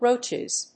/ˈrotʃɪz(米国英語), ˈrəʊtʃɪz(英国英語)/